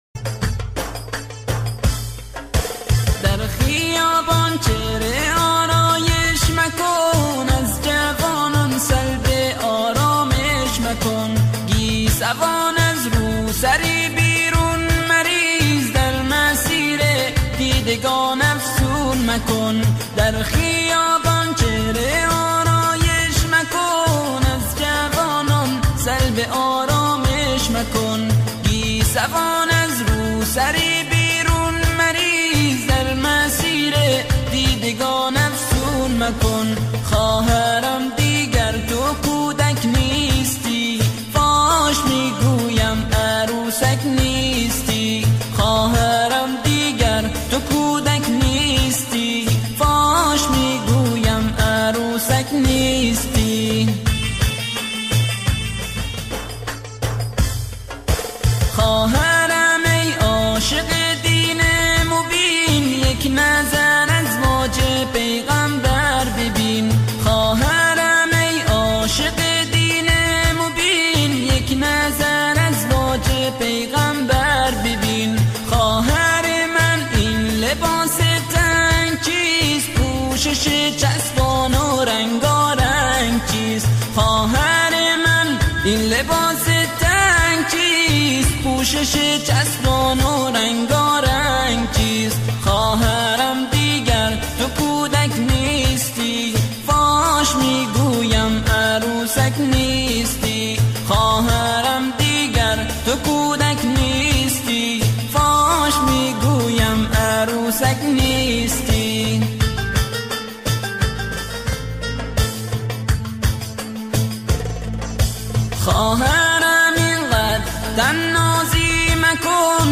نشید زیبا